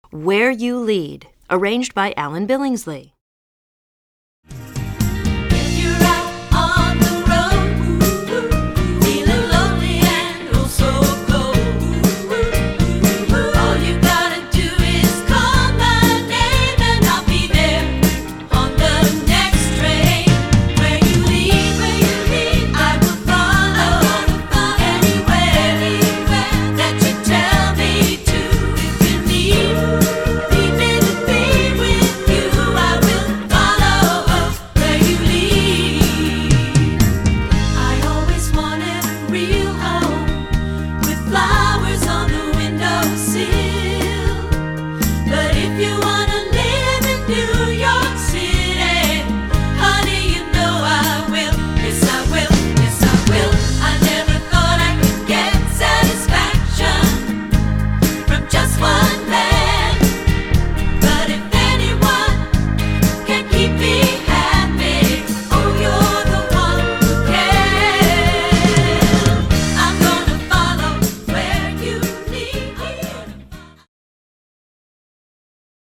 Choral Women's Chorus 70s-80s-90s Pop
SSA